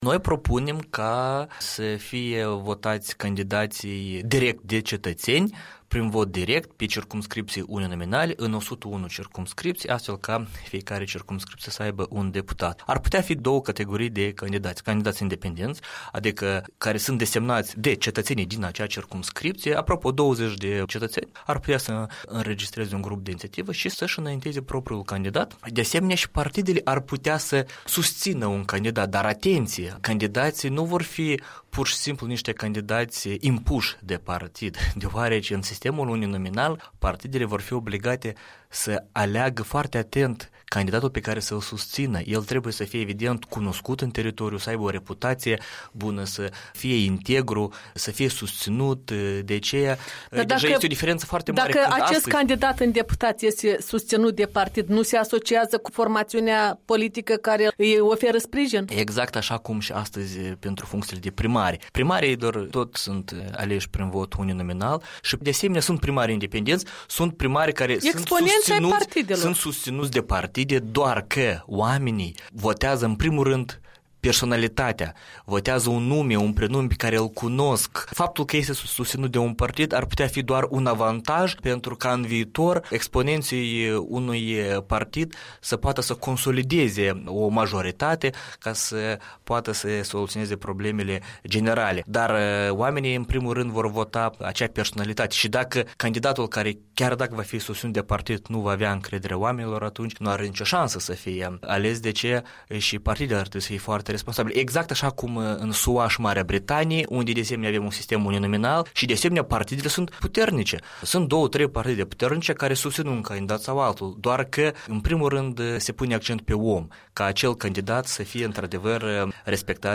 În dialog cu deputatul Sergiu Sîrbu (PD)